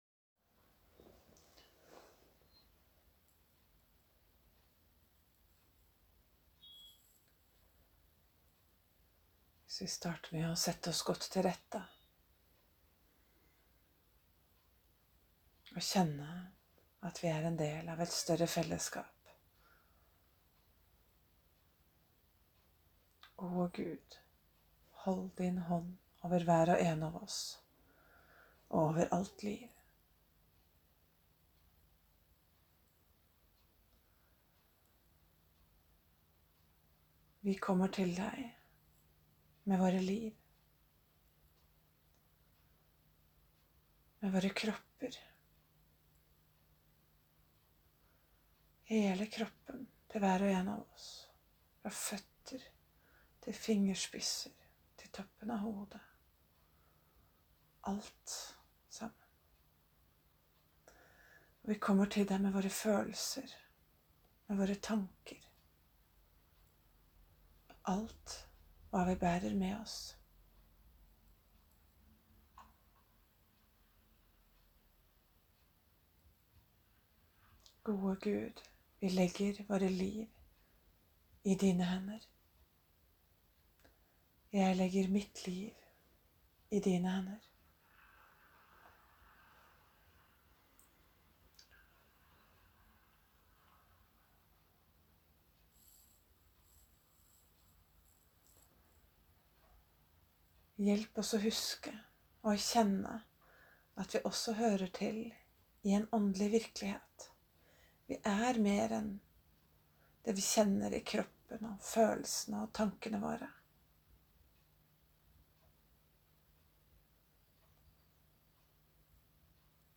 Meditativ morgenbønn
Om noen ønsker ytterligere hjelp i praksisen, kan dere kanskje ha glede av lydfiler med guidet meditativ bønn.
Det er ikke optimal lydkvalitet, men håper det er hørbart.